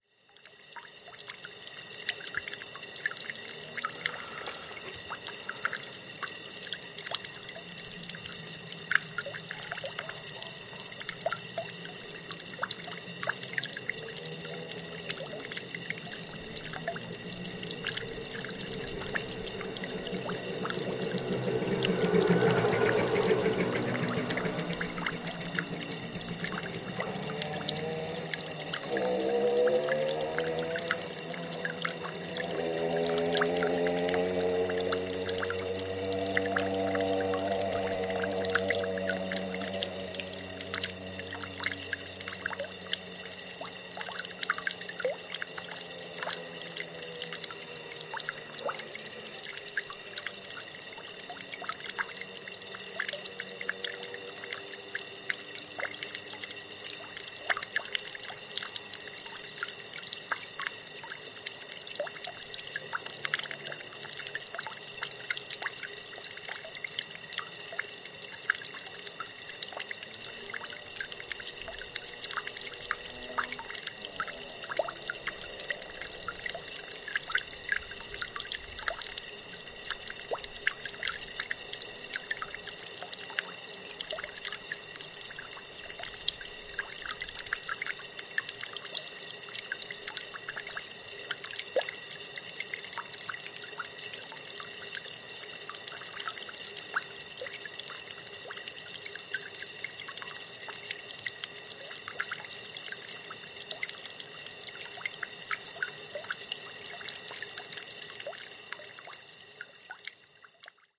Gravação do som da água a correr intermitentemente numa fonte. Gravado com Fostex FR-2LE e um par de microfones shotgun Rode NTG-2
Tipo de Prática: Paisagem Sonora Rural
Quintãs-Fonte-de-Água.mp3